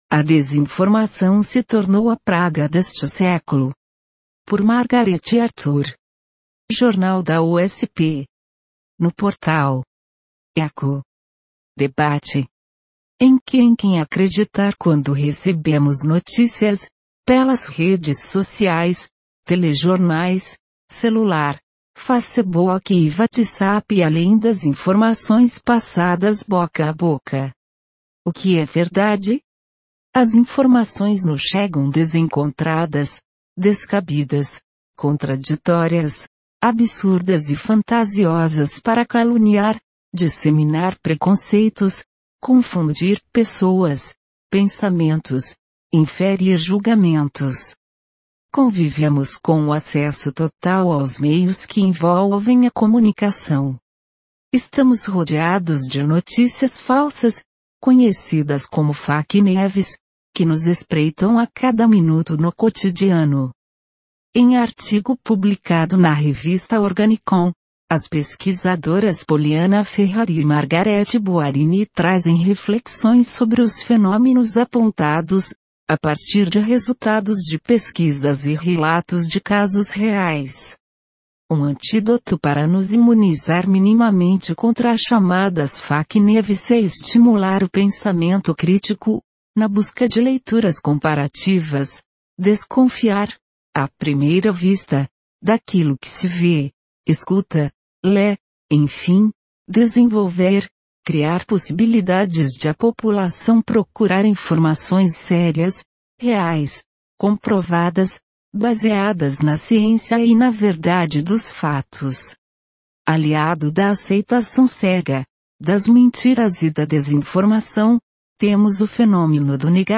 Sinopse em áudio, mp3, da matéria “A desinformação se tornou a praga deste século”